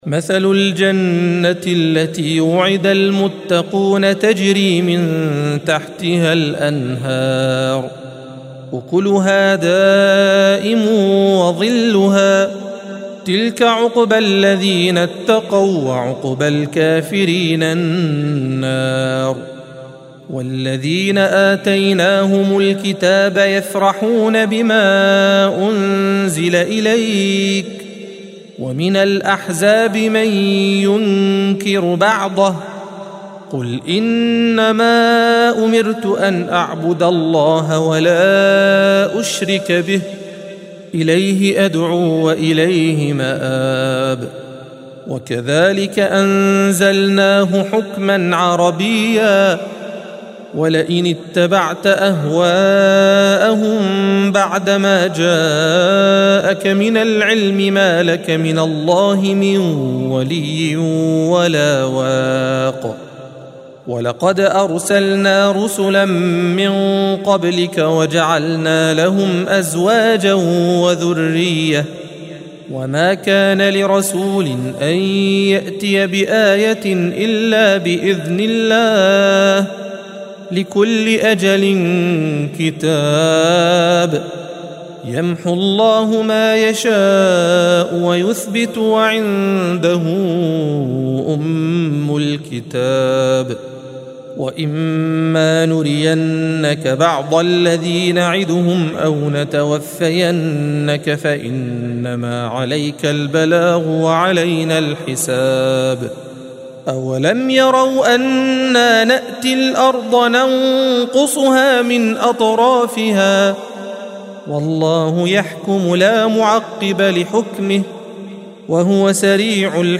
الصفحة 254 - القارئ